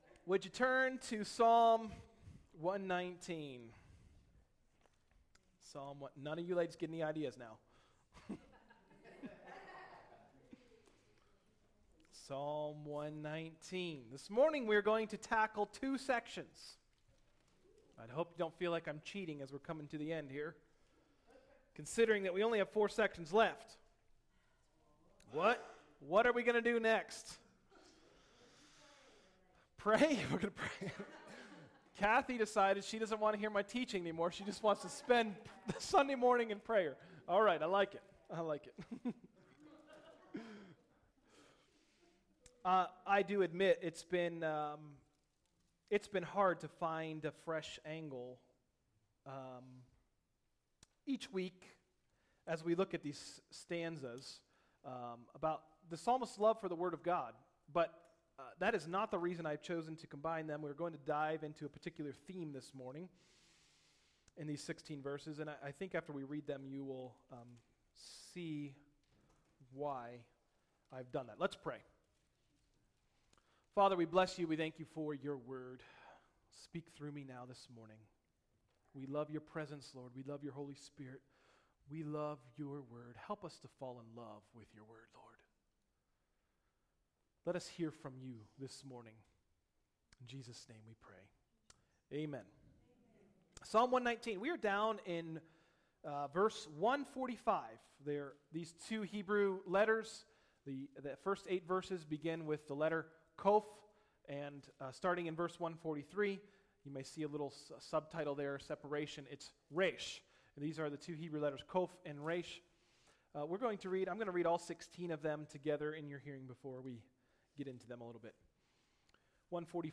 Message: “Qoph & Resh: Psalm 119” – Tried Stone Christian Center